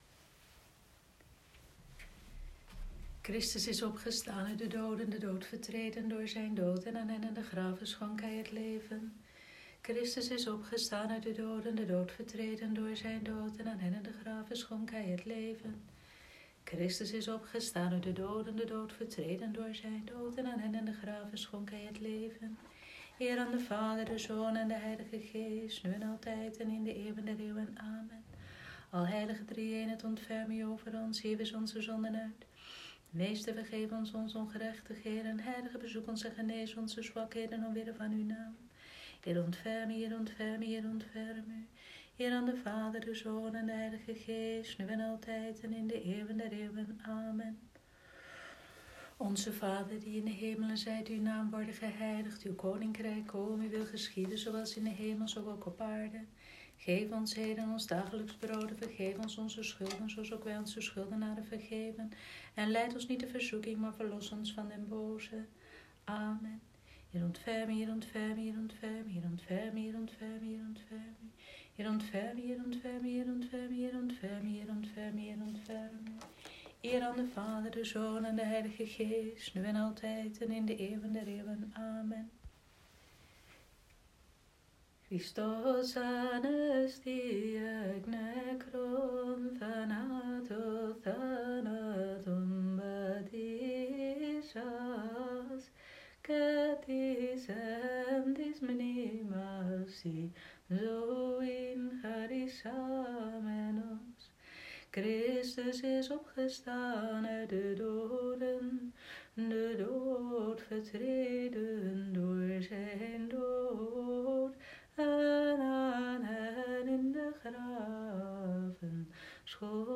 Vespers maandagavond, 27 april 2020
Vespers-maandagavond-27-april.m4a